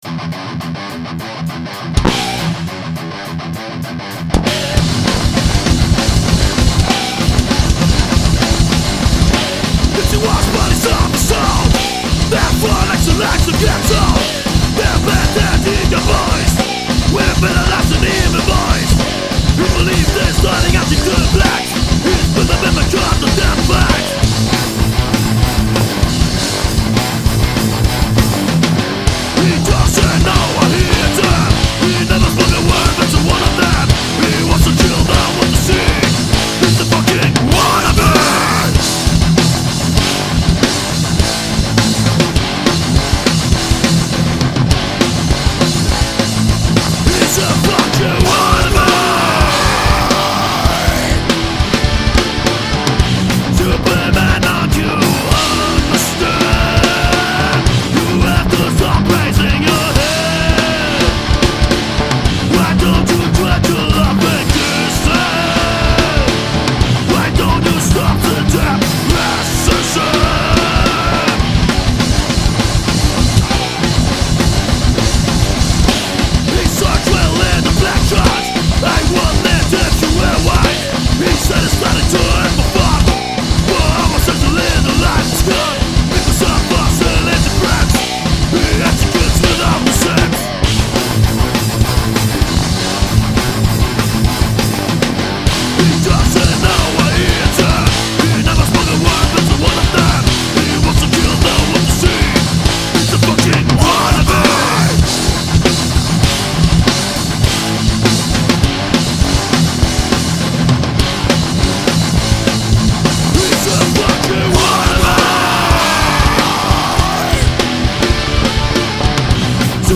Gitarre Schlagzeug und Bass präsent.
Drums
Vocals & Rhythm Guitar
Lead- & Solo Guitar